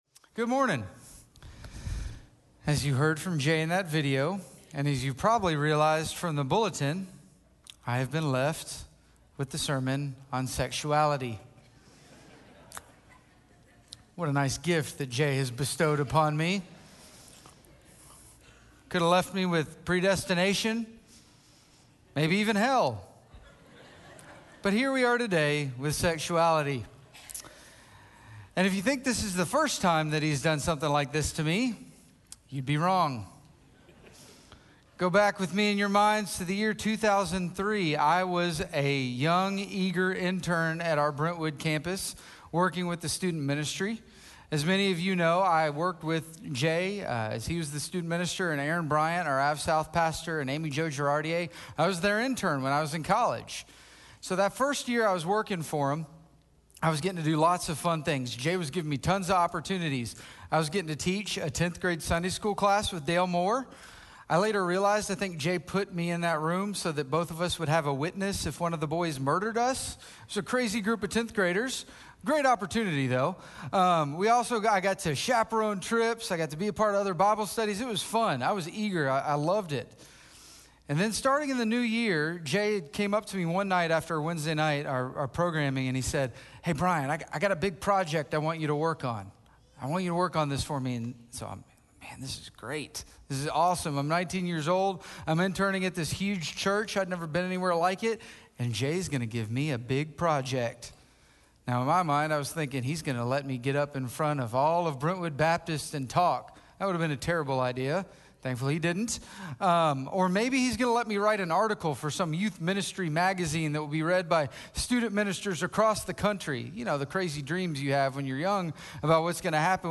Finding Our Way with Sexuality - Sermon - Station Hill